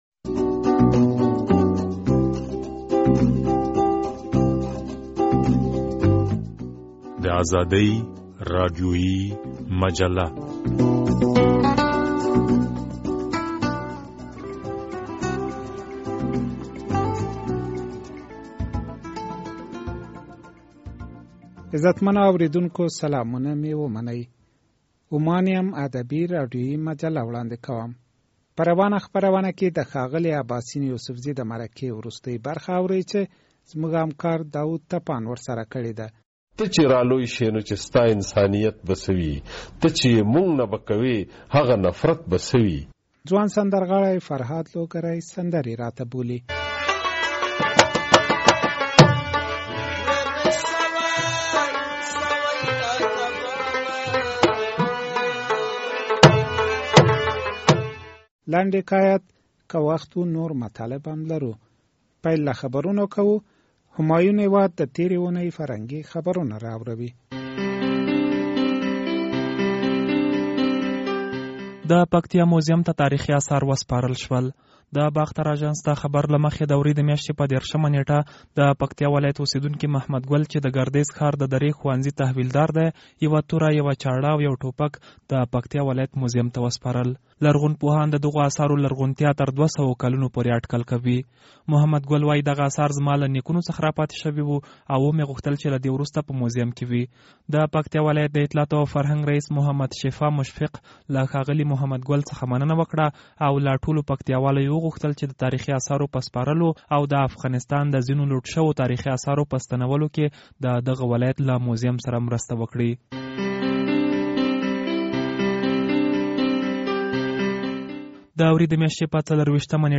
د اباسین یوسفزي د مرکې وروستۍ برخه